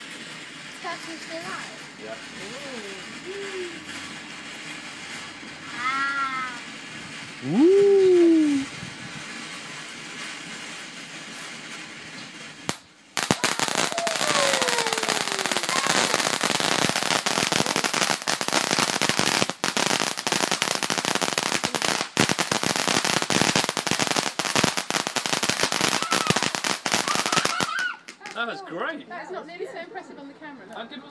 Garden Fireworks